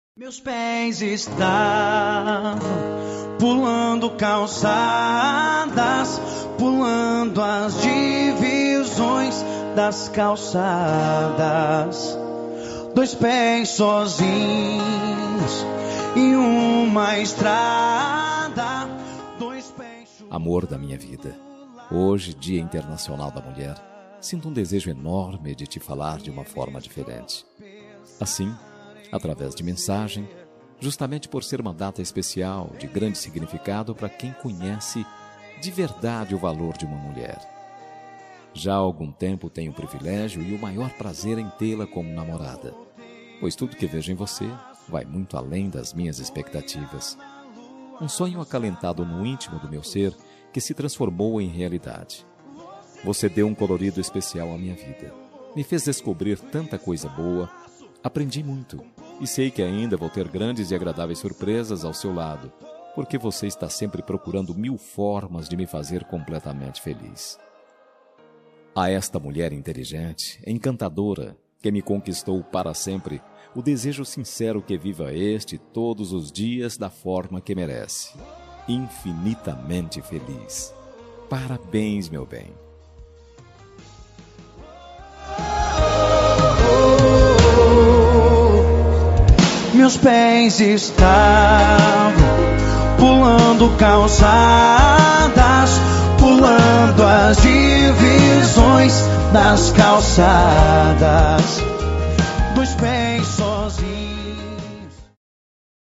Dia das Mulheres Para Namorada – Voz Masculina – Cód: 53020 – Linda
5302-dm-rom-masc.m4a